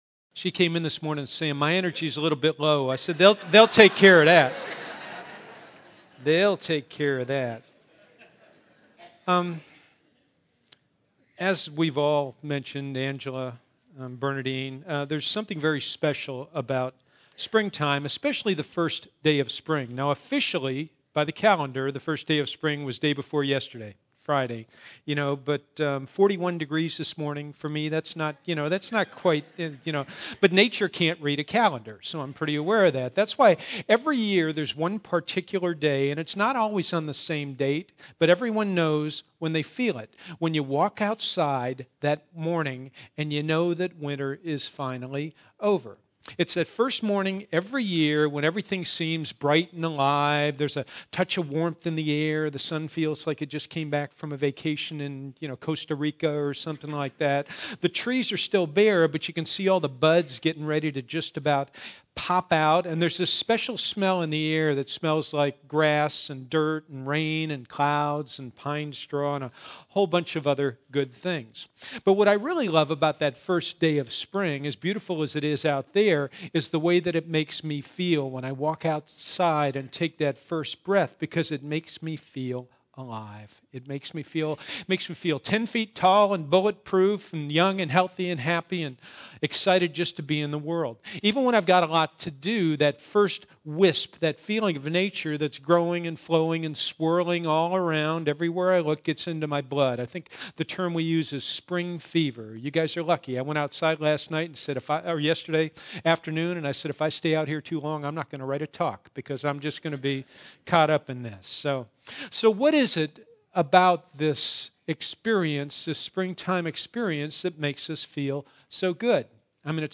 Our one-hour Sunday services are open and comfortable, with music, laughter, and interesting talks.